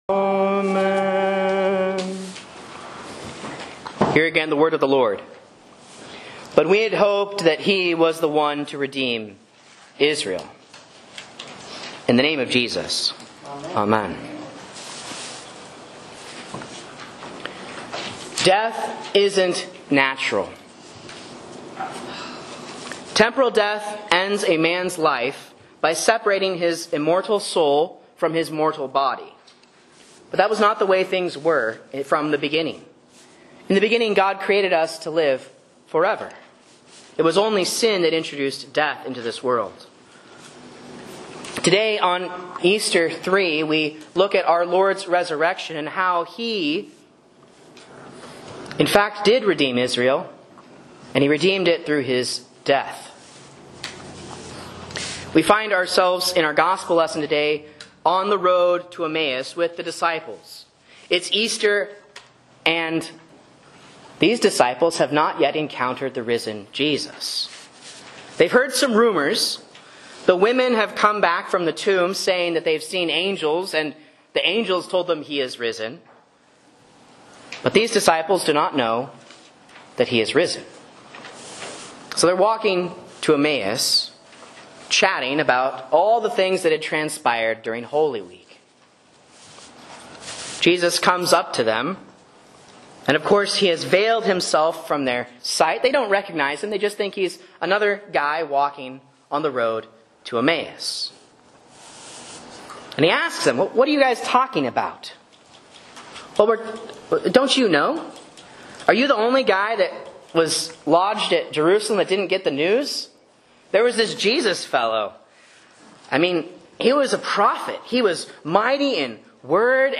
Sermon and Bible Class Audio from Faith Lutheran Church, Rogue River, OR
A Sermon on Luke 24:13-35 for Easter 3 (A)